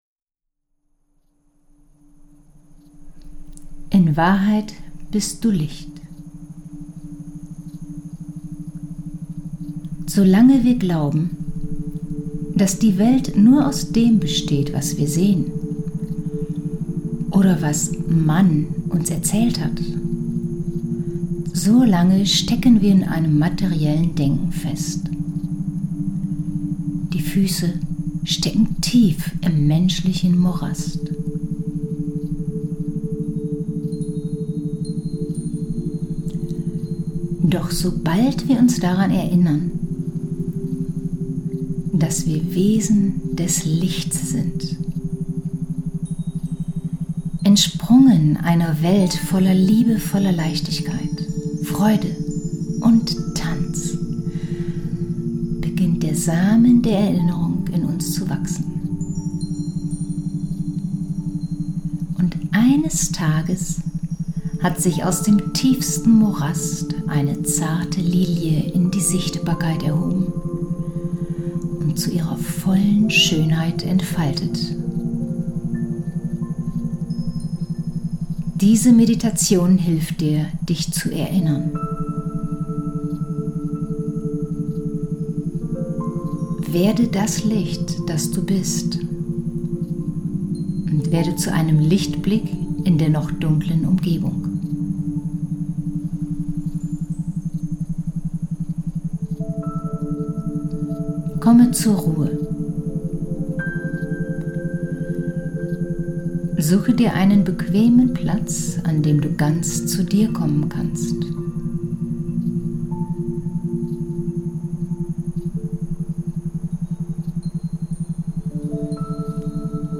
Meditation: In Wahrheit bist Du Licht